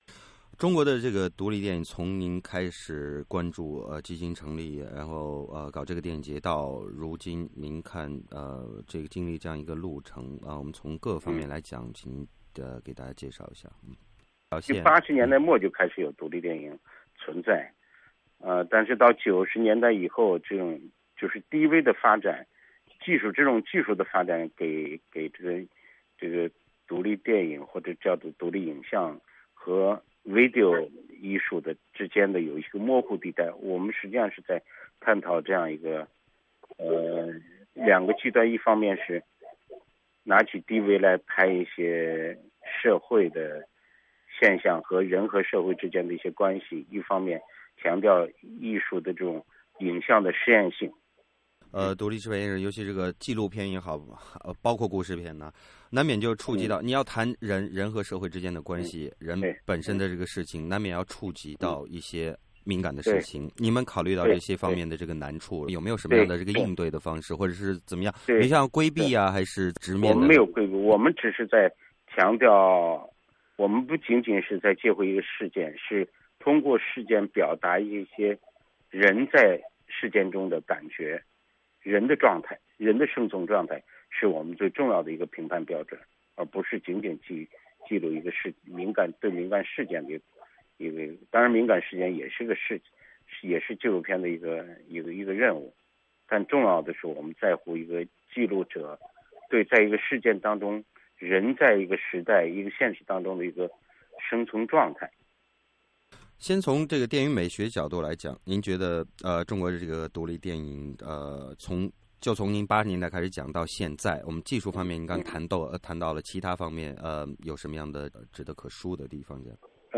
原定于8月23至31日举办的北京宋庄第11届独立影像展在开幕前夕，被北京通州区警方强迫取消。美国之音采访了该独立影展，以及独立电影基金的创始人栗宪庭。这位在中国广受尊敬的艺术评论家讲述了此次事件的经过，并谈论了他对于中国独立电影现状及未来发展的看法。